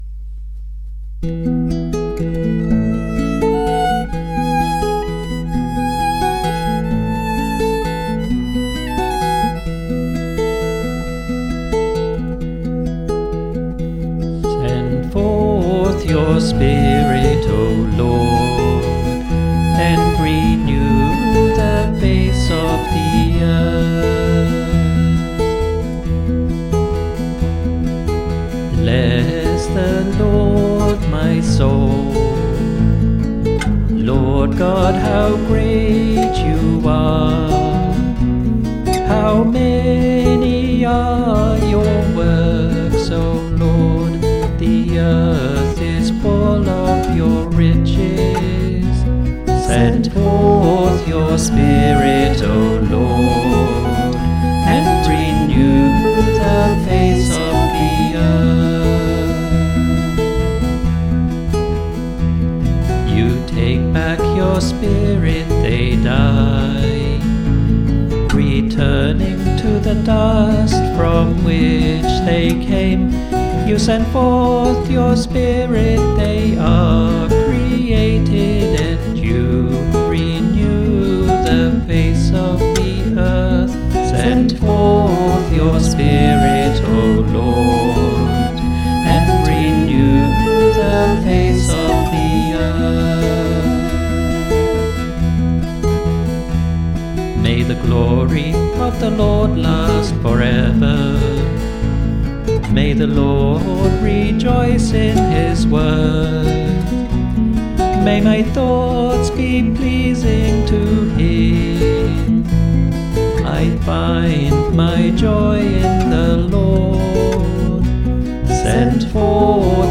Response: Send forth Your spirit, O Lord, and renew the face of the earth.
Musical performance by the Choir of Our Lady of the Rosary RC Church, Verdun, St. John, Barbados.